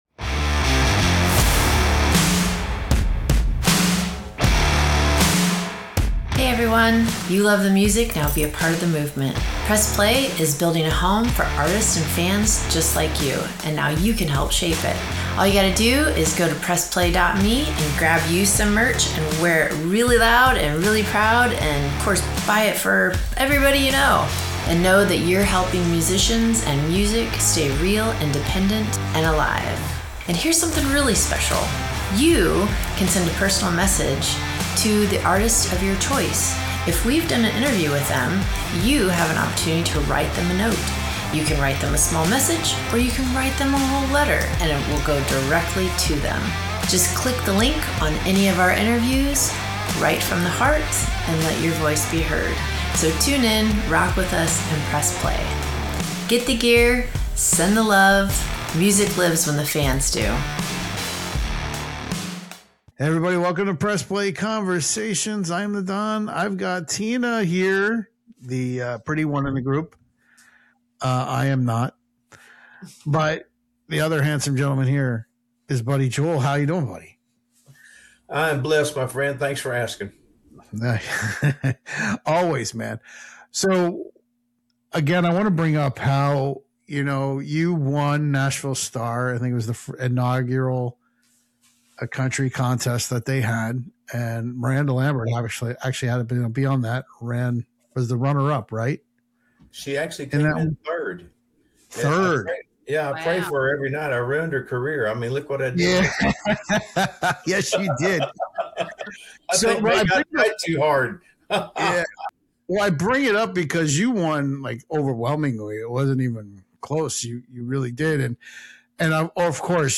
It’s a conversation about faith, resilience, classic country roots, and why authenticity still matters in today’s streaming world.